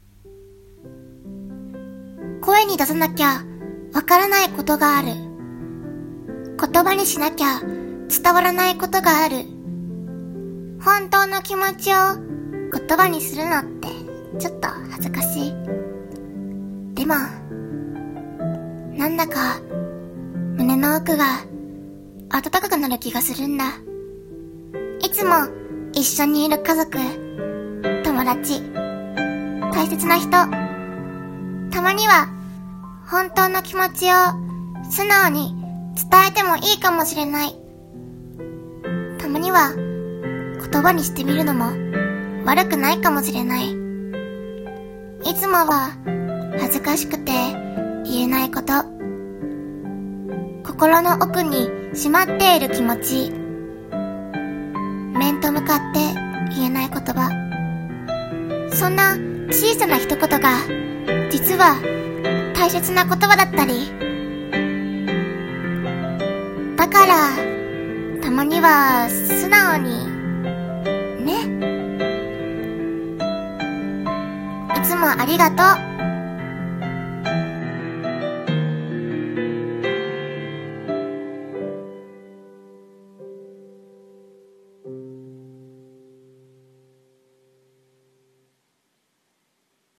[声劇･朗読]たまには素直に